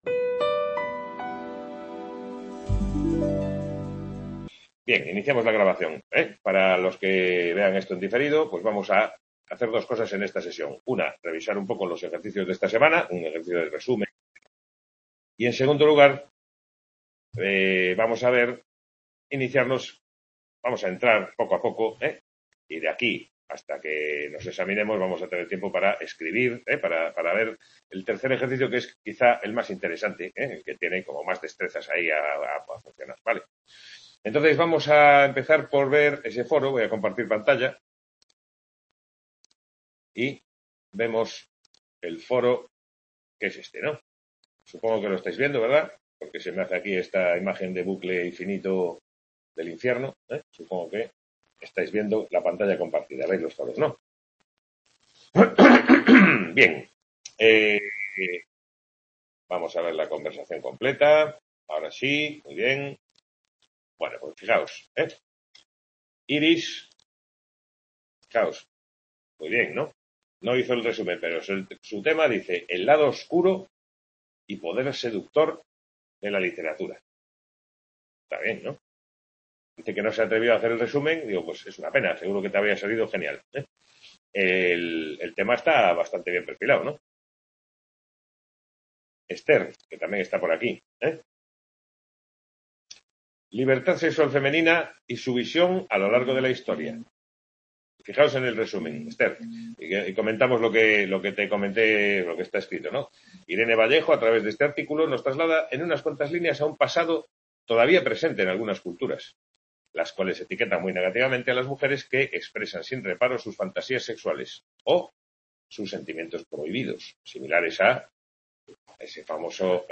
Tutoría (24/11/2021) Description Sesión de tutoría de la asignatura Comentario de Texto dedicada a dar los primeros pasos en la preparación del ejercicio de opinión personal acerca de un artículo de actualidad.